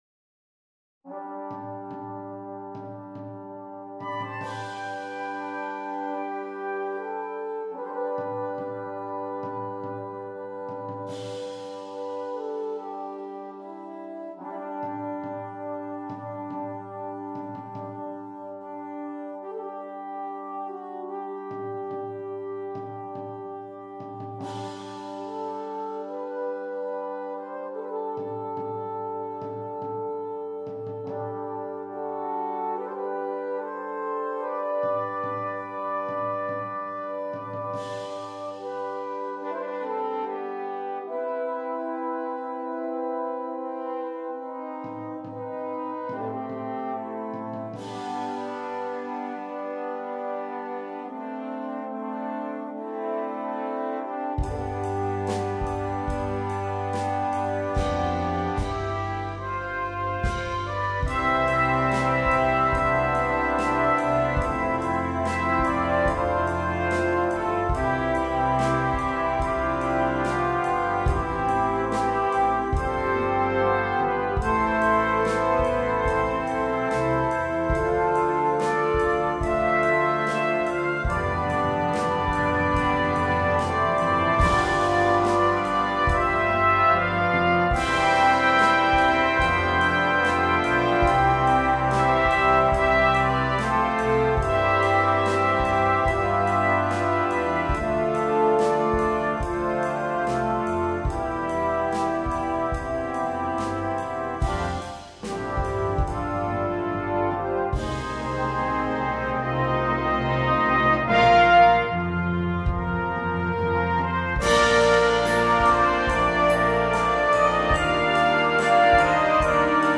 The song concludes with the solo flugle and horn.